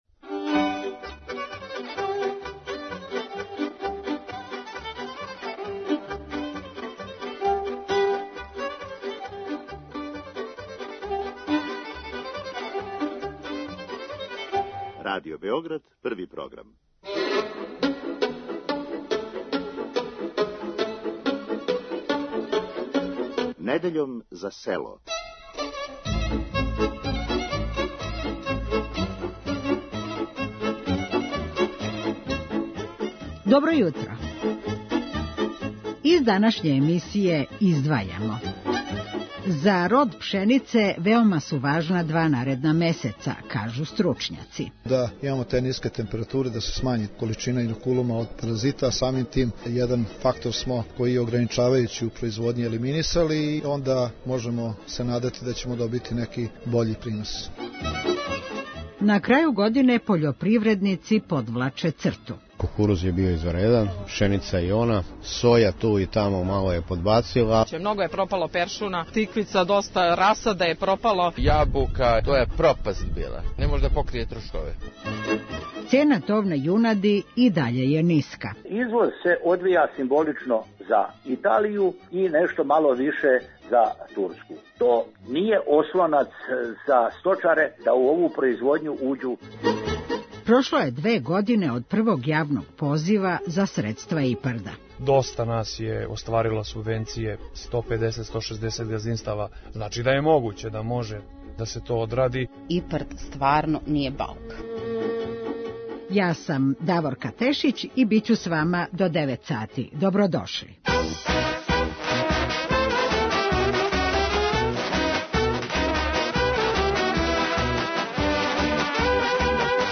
О години на измаку разговарали смо са повртарима, воћарима, виноградарима, сточарима...